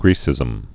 (grēsĭzəm)